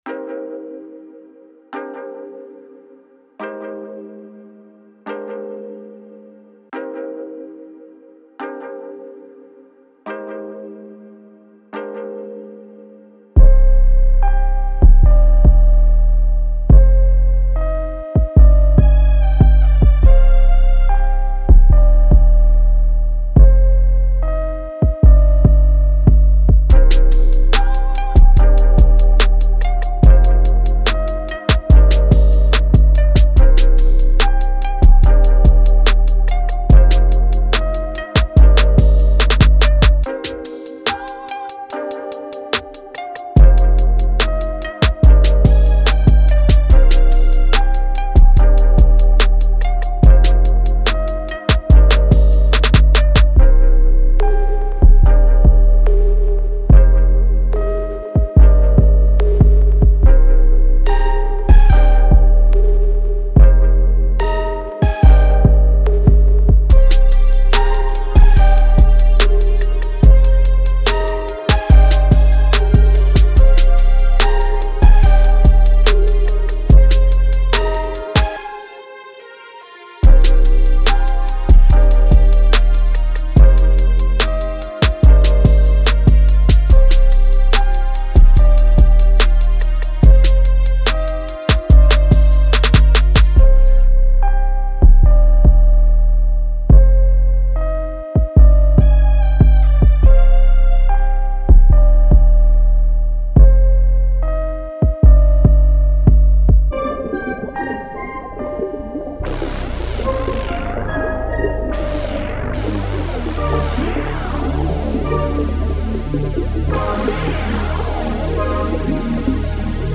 144 D# Minor